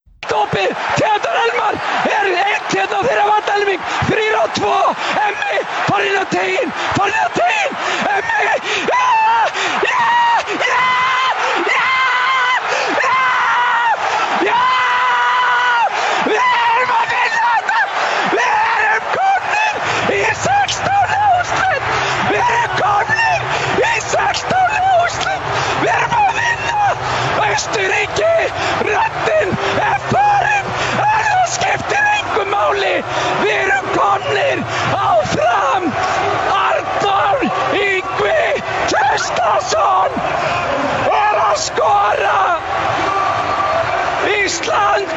Unten findet Ihr die Reportagen zu acht berühmten Toren, bei denen sich die Kommentatoren besonders ins Zeug gelegt haben, verbunden mit jeweils einer Frage.